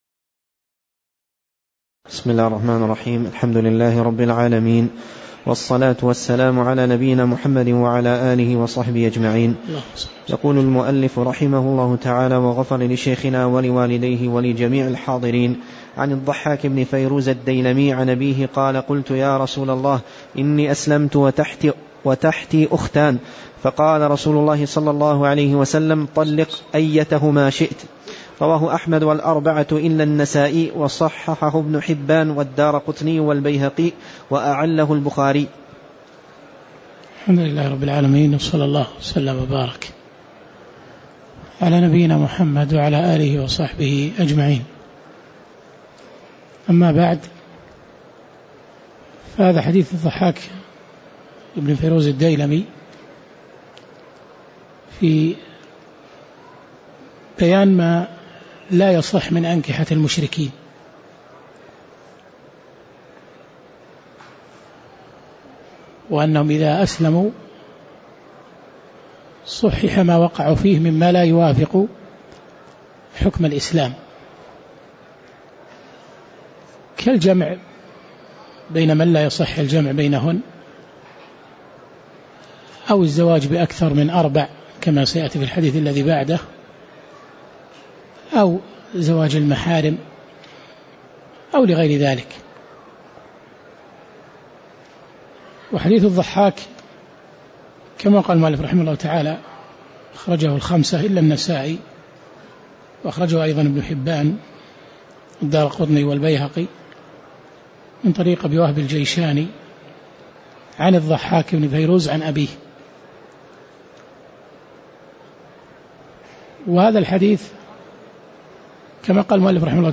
تاريخ النشر ٢٣ ربيع الثاني ١٤٣٧ هـ المكان: المسجد النبوي الشيخ